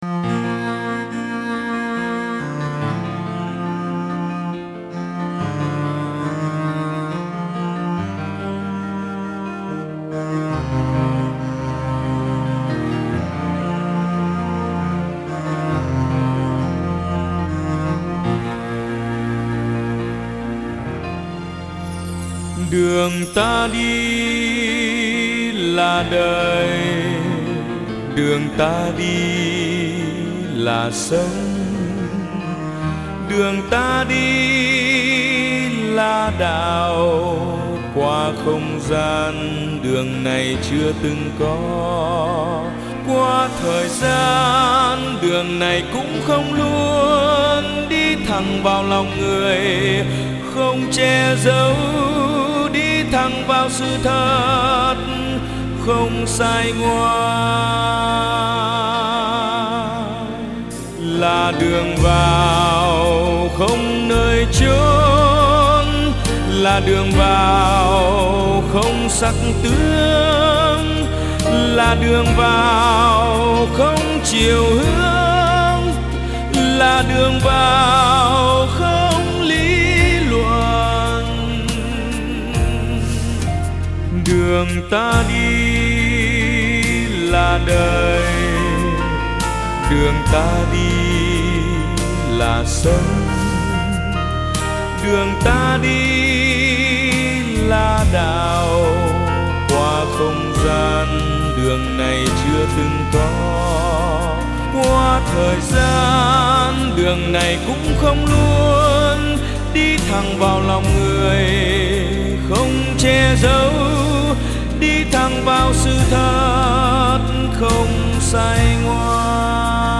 Nhạc Phật Giáo